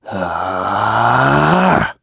Clause_ice-Vox_Casting2.wav